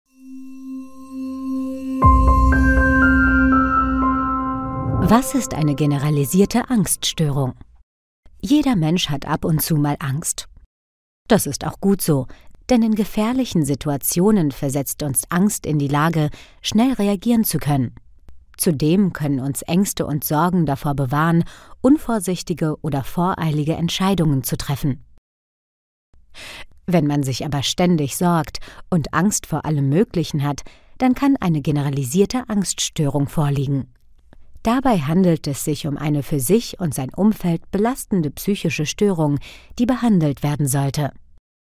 Narración médica
Micrófono: Neumann TLM 103